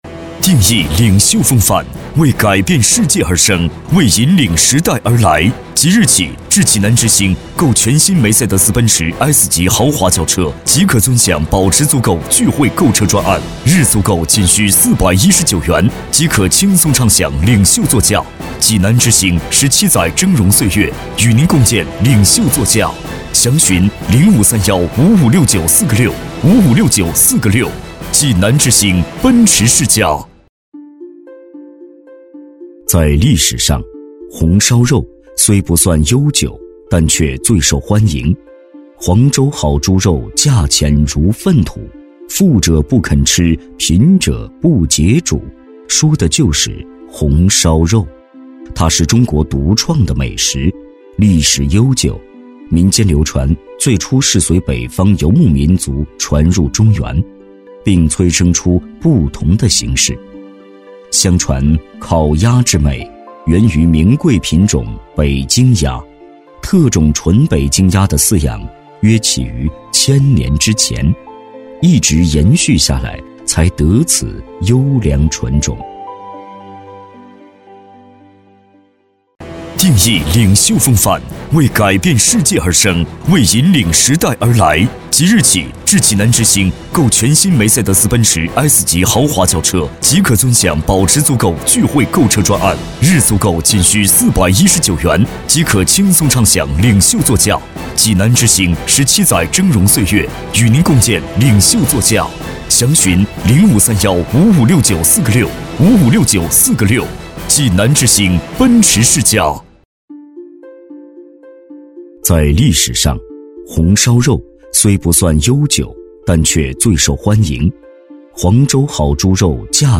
职业配音员全职配音员浑厚大气
• 男S353 国语 男声 广告-餐饮广告-平实陈述 大气浑厚磁性|科技感|积极向上